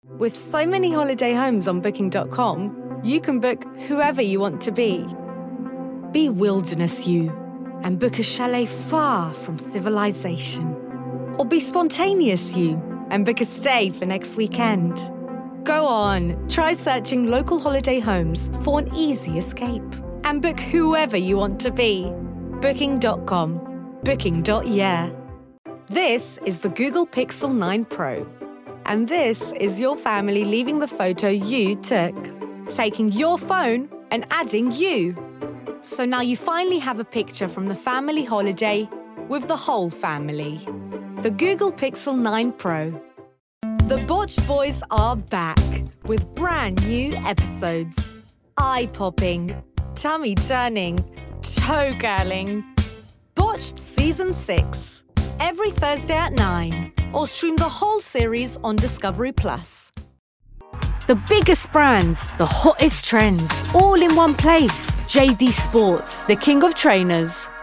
Albanian, Female, Home Studio, Teens-30s